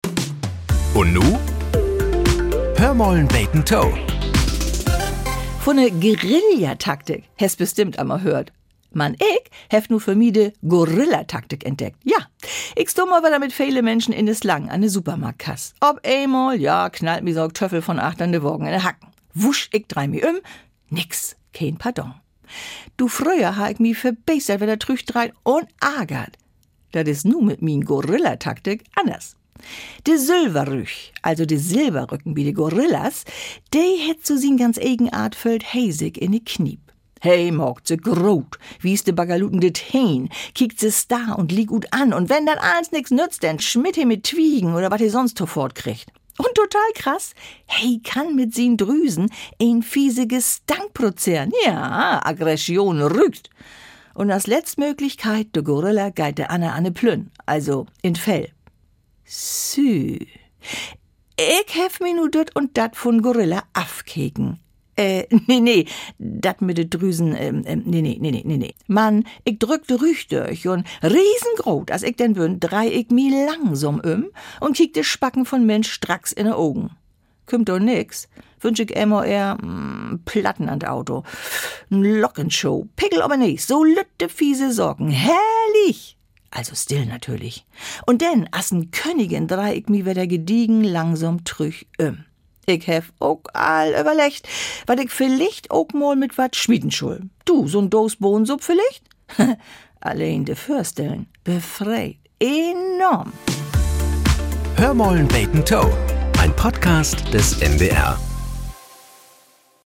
Nachrichten - 03.04.2025